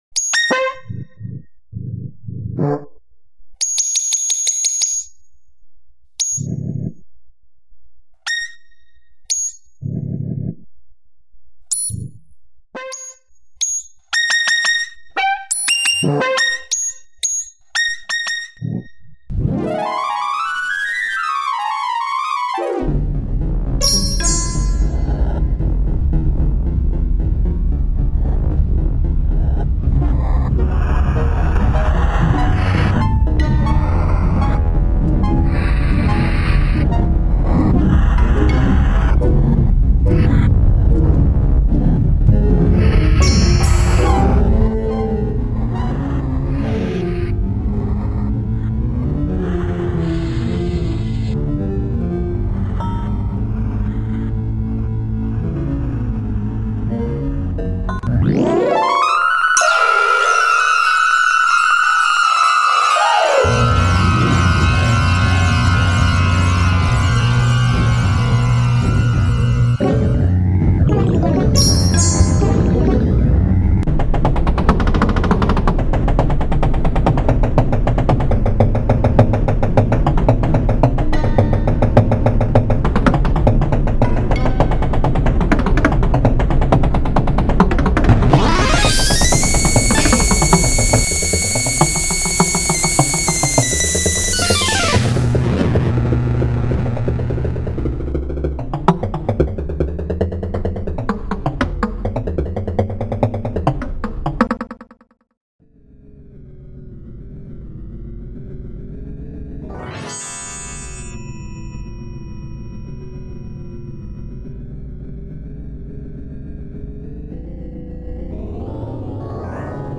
Électroacoustique